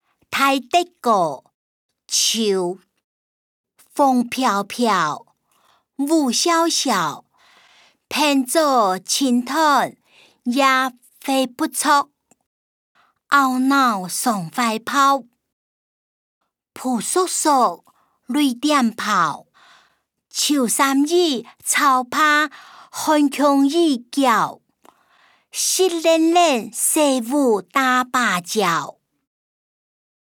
詞、曲-大德歌‧秋音檔(饒平腔)